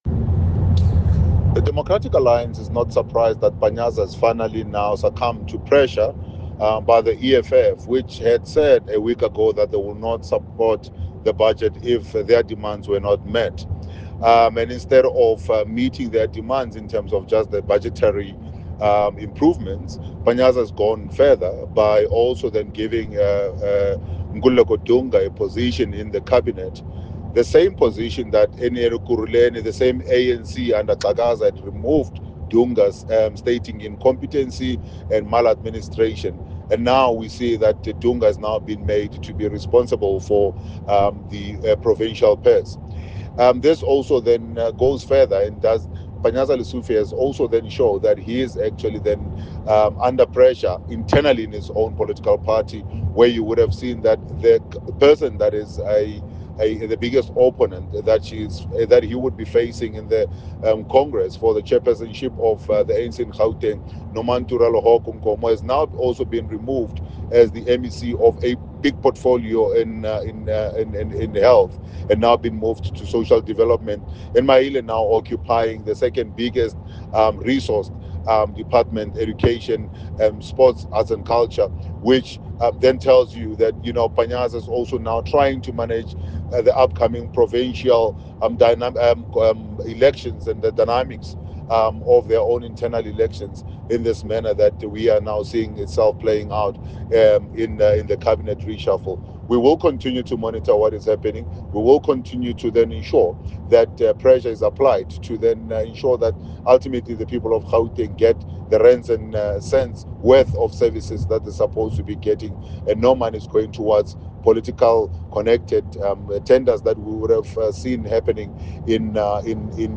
Note to Editors: Please find a soundbite by DA MPL, Solly Msimanga, in English